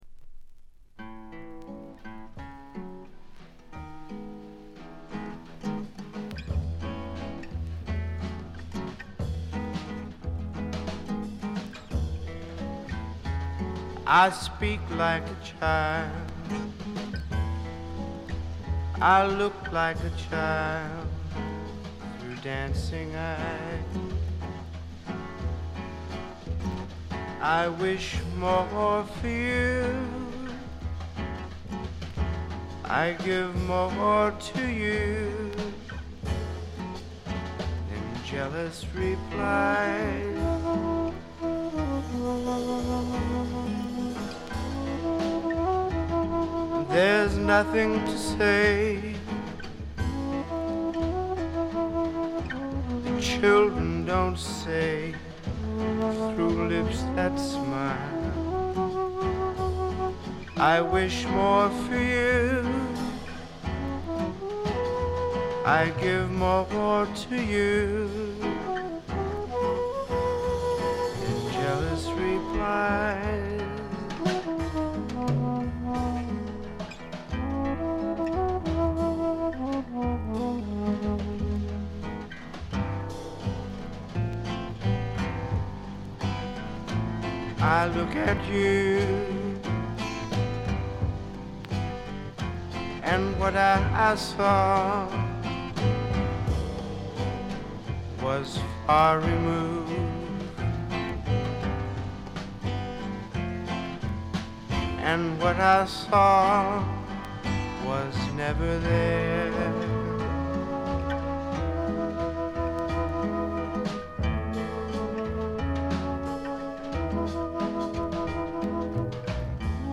バックグラウンドノイズ、チリプチ多め大きめ。
フォーク、ロック、ジャズ等を絶妙にブレンドした革新的ないでたちでの登場でした。
試聴曲は現品からの取り込み音源です。